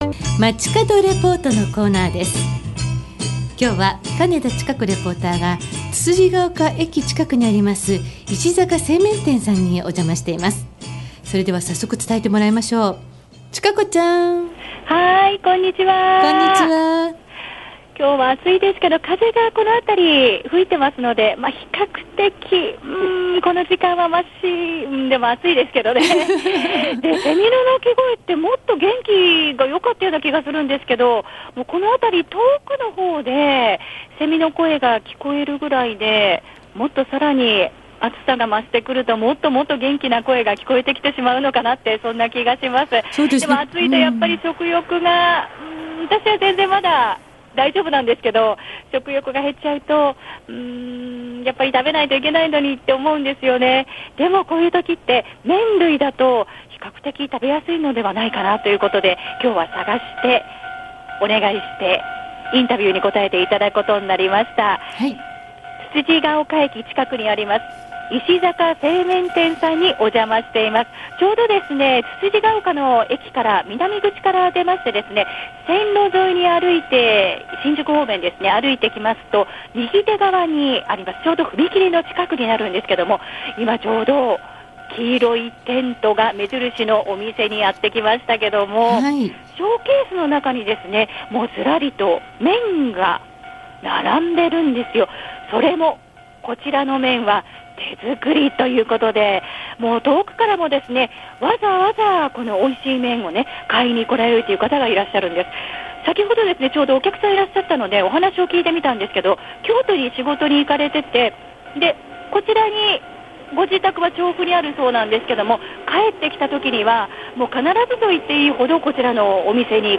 街角レポート
石坂製麺店 お客様に伺ってみました。